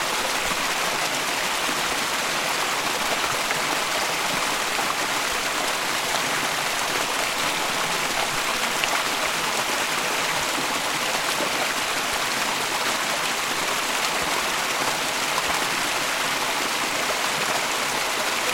Waterfall.wav